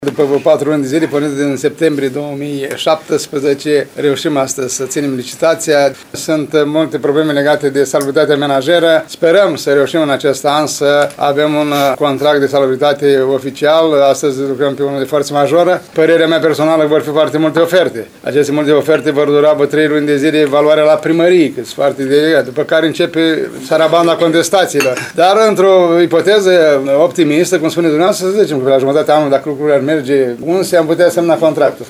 Primarul ION LUNGU a declarat astăzi că evaluarea ofertelor se va face în următoarele 3 luni.